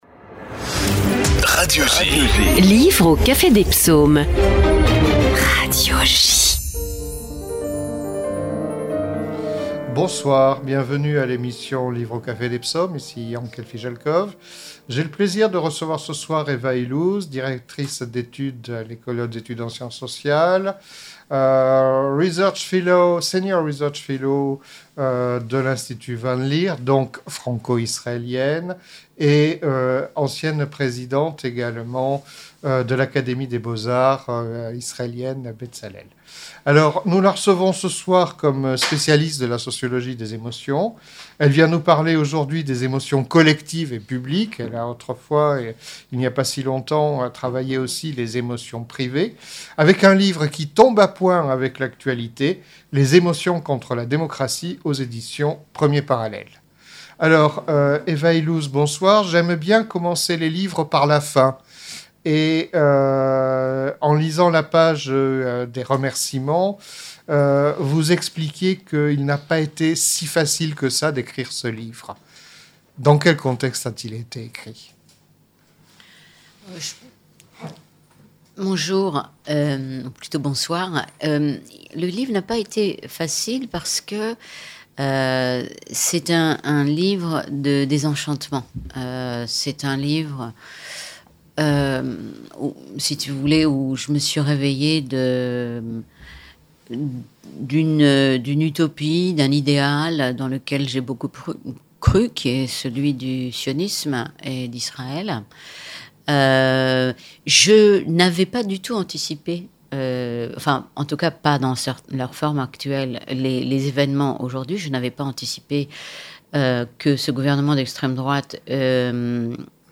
Rencontre avec Eva Illouz